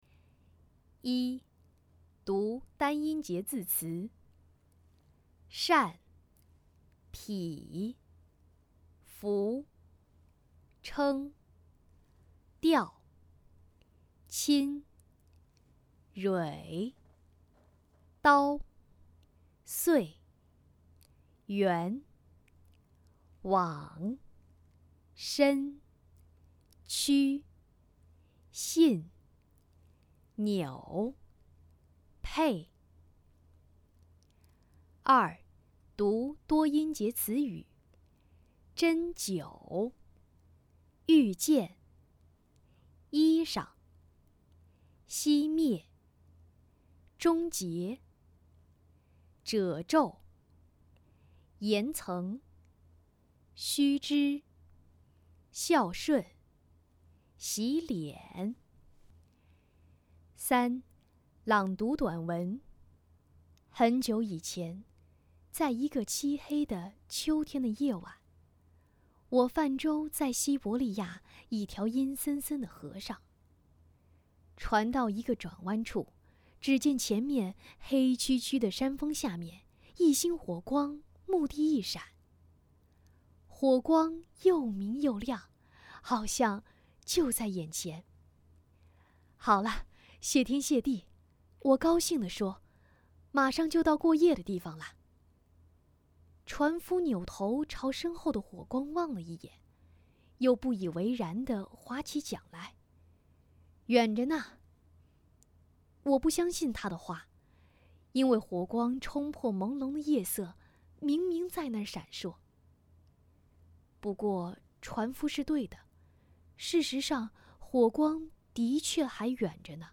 领读课件